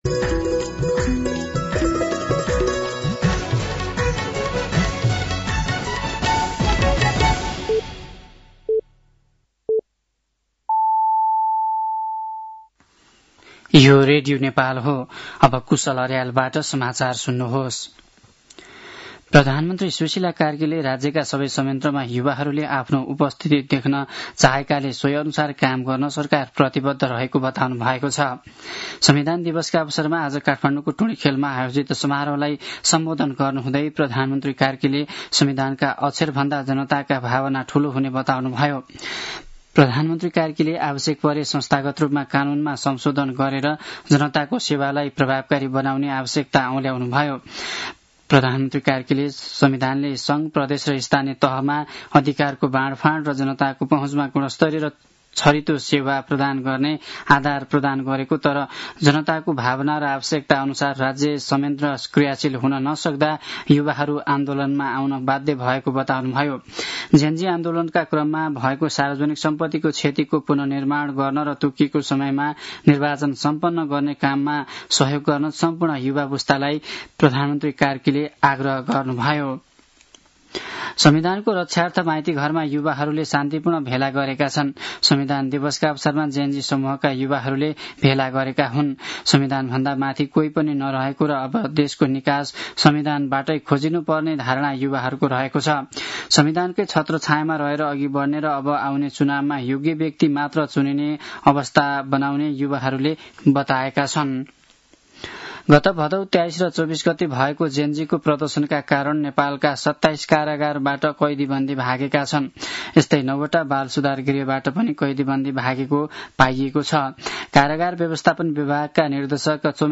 साँझ ५ बजेको नेपाली समाचार : ३ असोज , २०८२